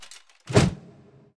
tank_attk1.wav